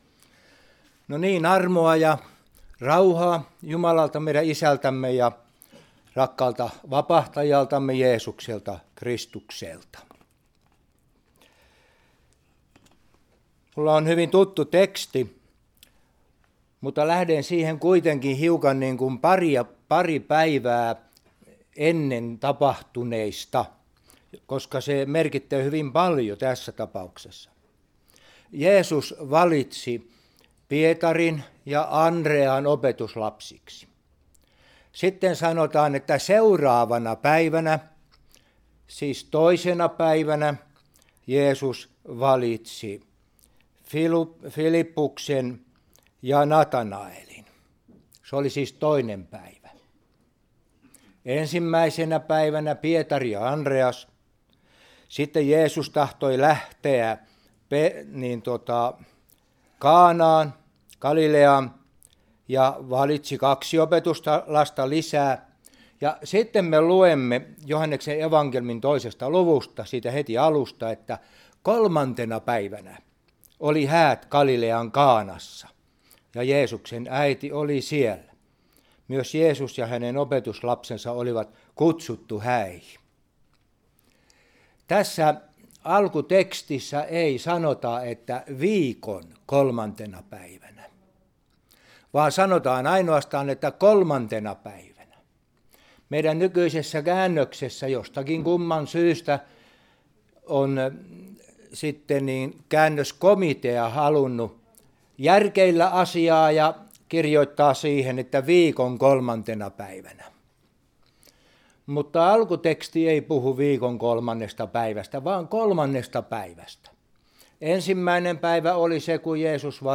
Raamattuopetus Toivontorstaina